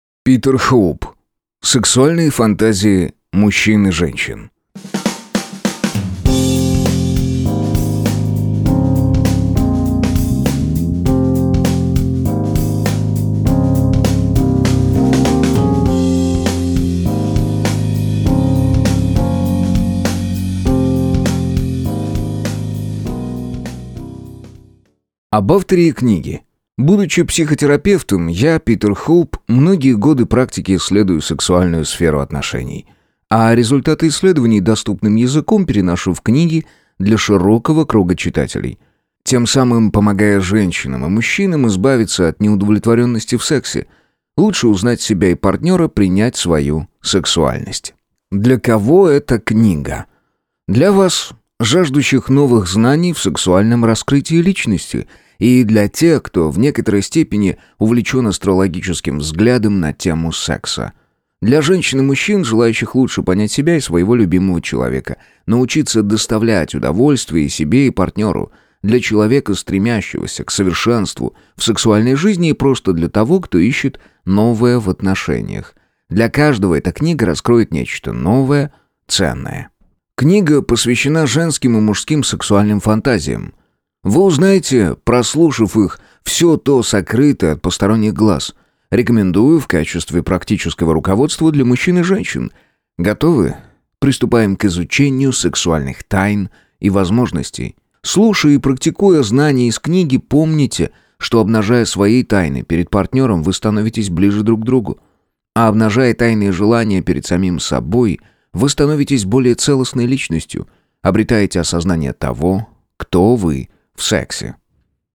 Аудиокнига Сексуальные фантазии мужчин и женщин | Библиотека аудиокниг